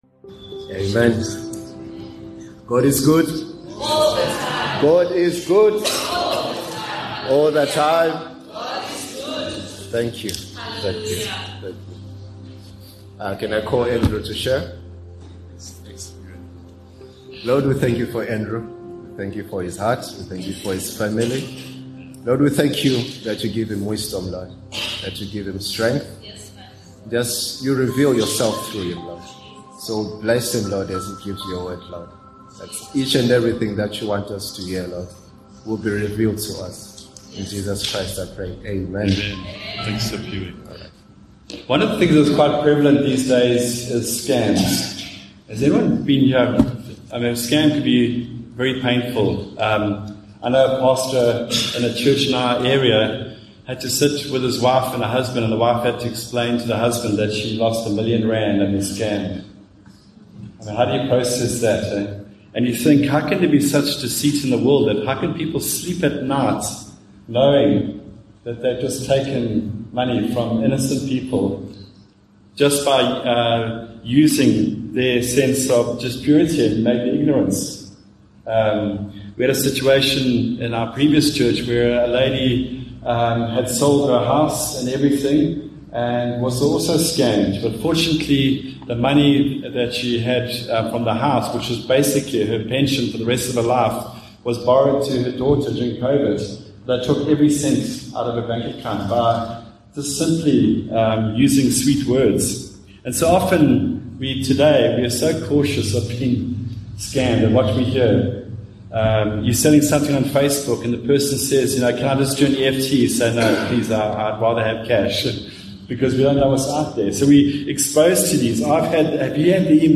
View Promo Continue JacPod Install Upper Highway Vineyard Sunday messages 18 Feb Rejoice in the PROMISE KEEPER 31 MIN Download (14.5 MB) Sunday message ....